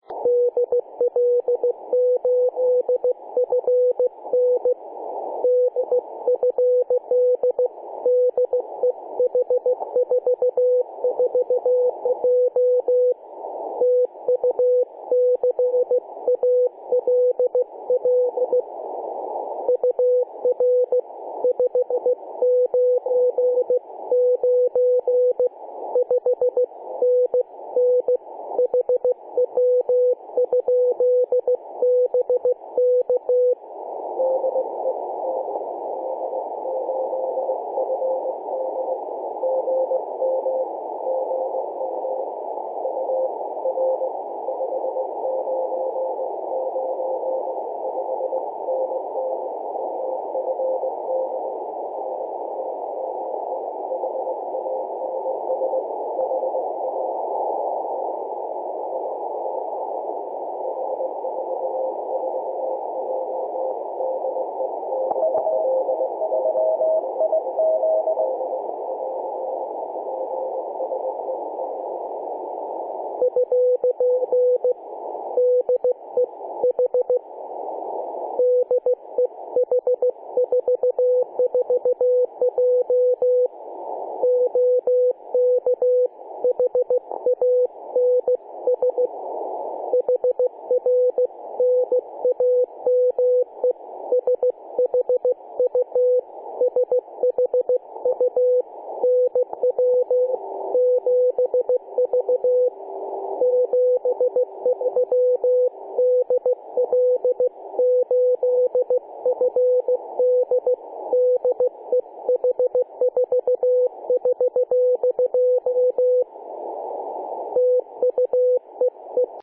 2013/Aug/19 0944z H44J 21.018MHz CW